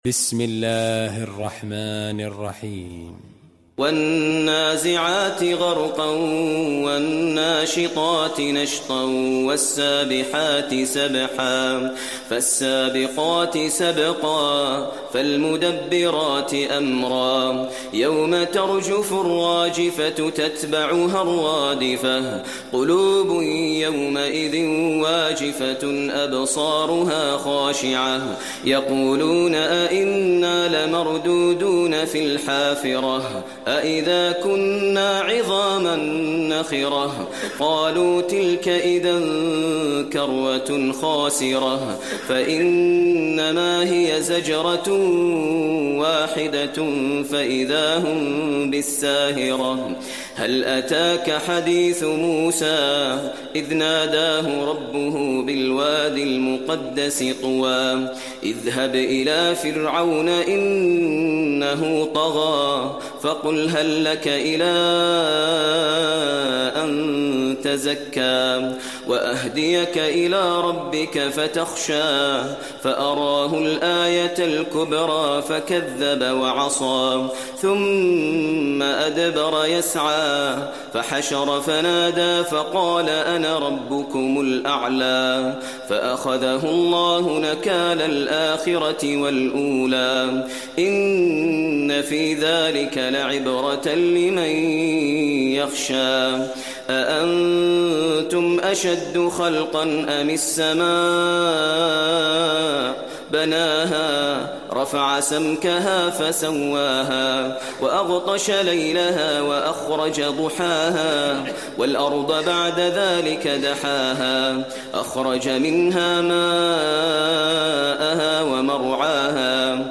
المكان: المسجد النبوي النازعات